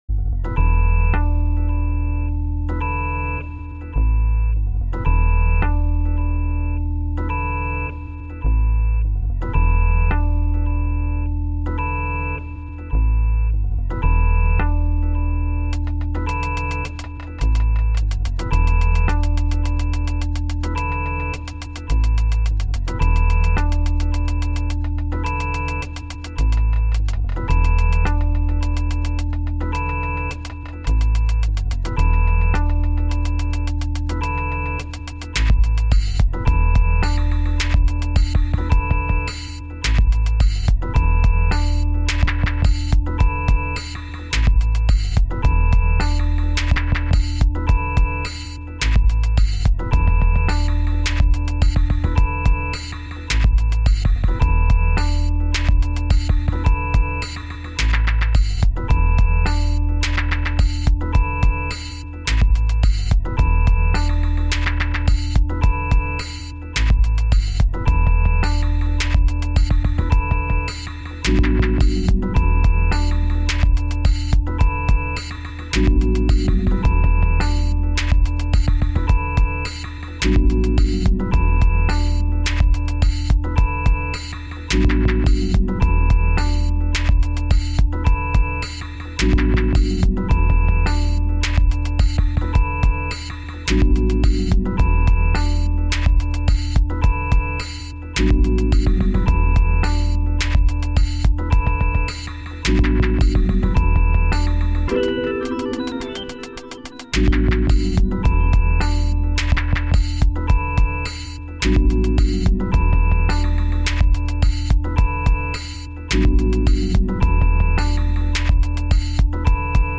Tune in today's "WGXC Afternoon Show" for an interview